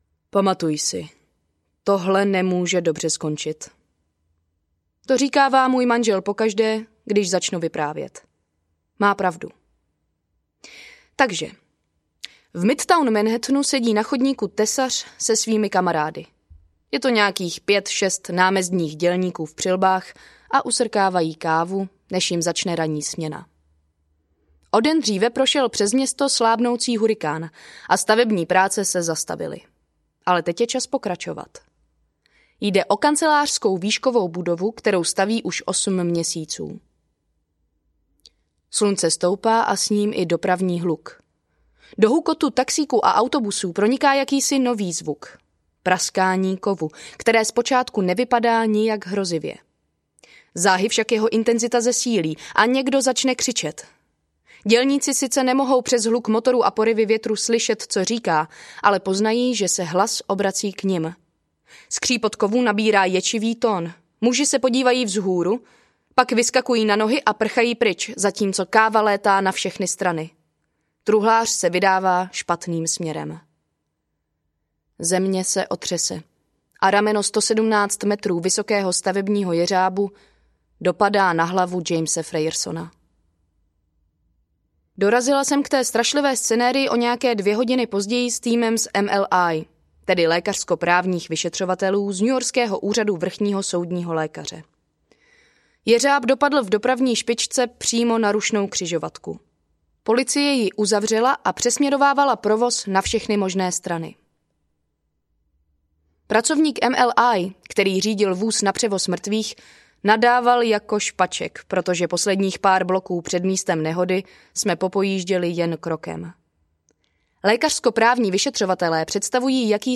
Řez do tuhého audiokniha
Ukázka z knihy